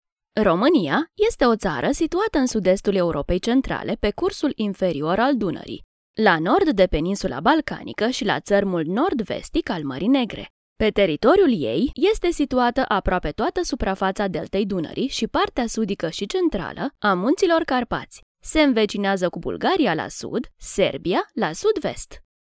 Zuverlässig, Freundlich, Warm, Sanft, Corporate
Erklärvideo
She can be professional in corporate e-learning but also nice and soft in stories for children.